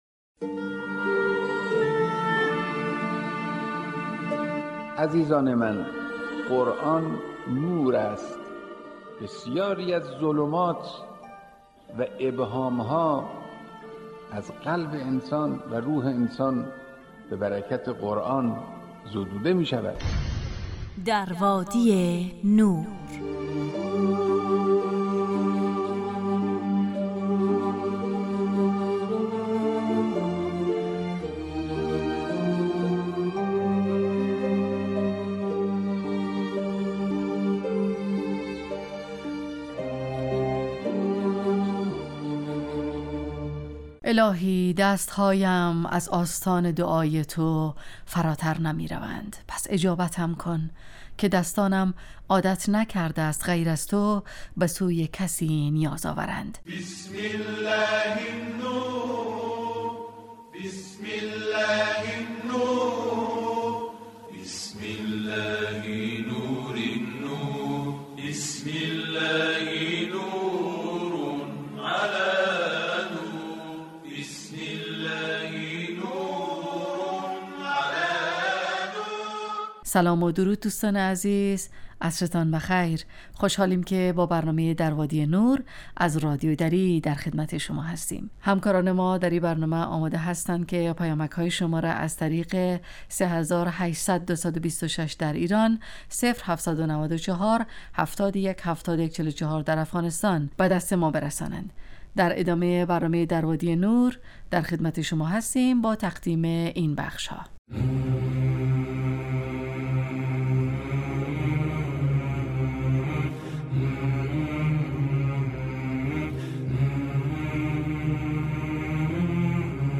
در وادی نور برنامه ای 35 دقیقه ای با موضوعات قرآنی روزهای فرد: ( قرآن و عترت،طلایه داران تلاوت، ایستگاه تلاوت، دانستنیهای قرآنی، تفسیر روان و آموزه های زند...